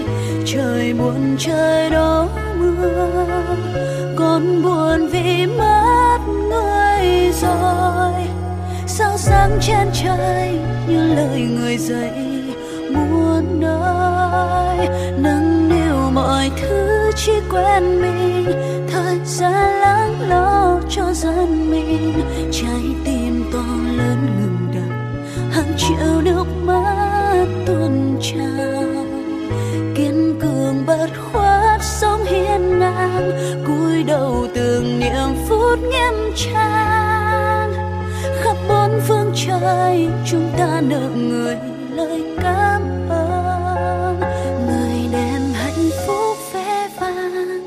Nhạc Trữ Tình hát về Bác Nguyễn Phú Trọng